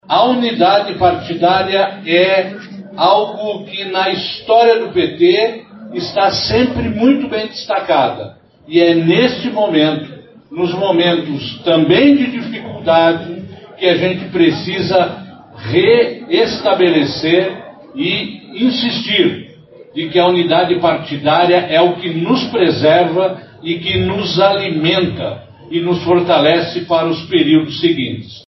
A convenção aconteceu na sede da Federação dos Trabalhadores nas Indústrias da Construção e do Mobiliário do Estado do Paraná (Fetraconspar), onde os filiados do PT votaram a favor do apoio ao pré-candidato do PSB para o Executivo.